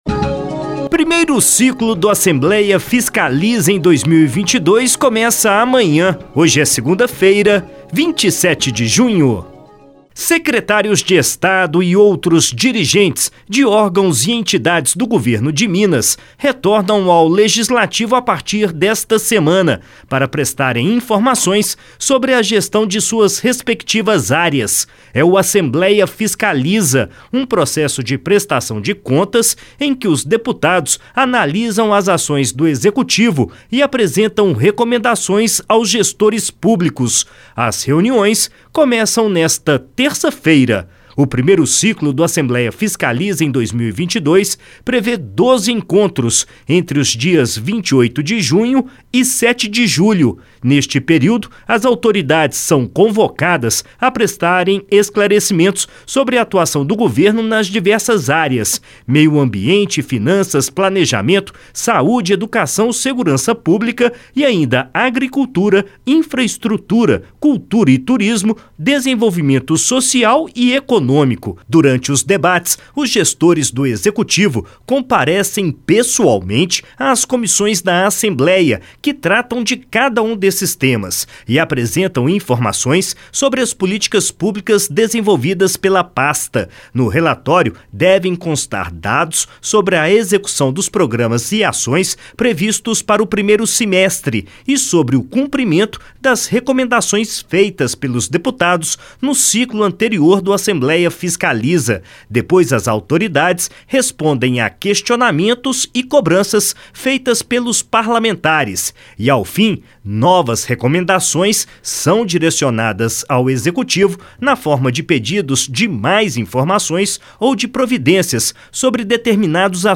Boletim da ALMG - Edição n.º 5414